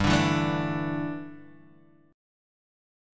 Dsus2/G chord